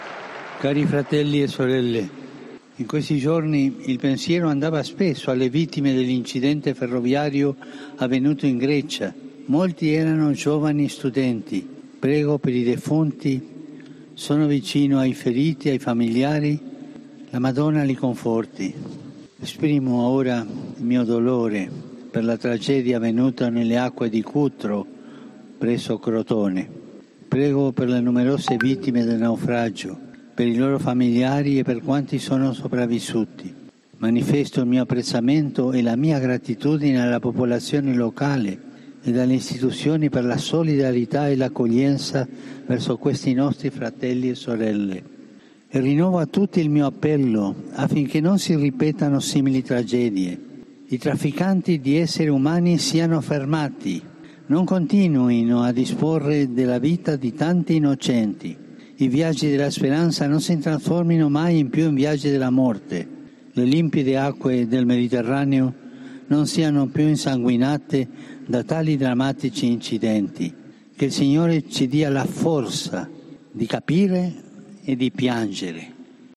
Escucha las palabras del Papa desde Plaza San Pedro